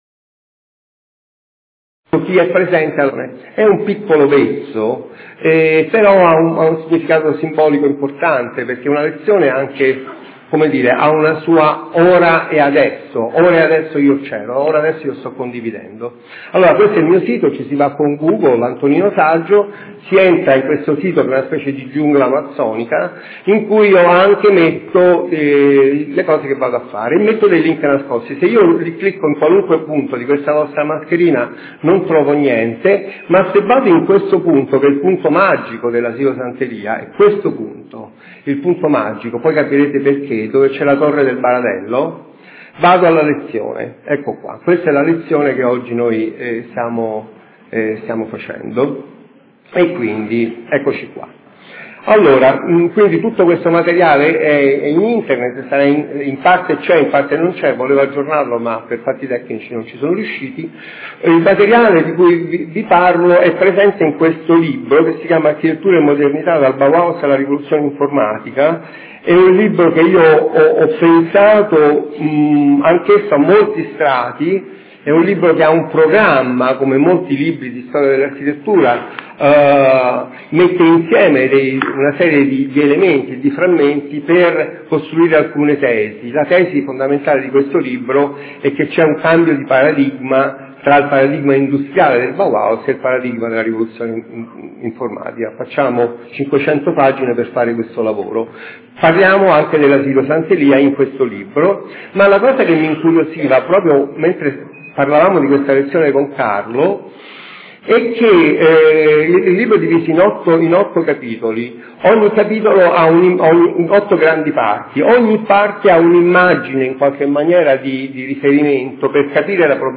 Giuseppe Terragni Lecture
Università di Genova 2015